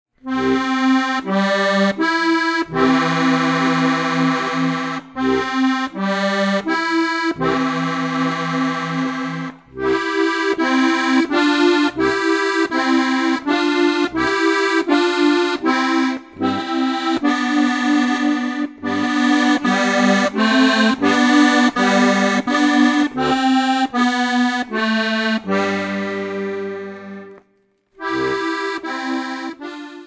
Die schönsten Weihnachtslieder für Steirische Harmonika
Besetzung: Steirische Harmonika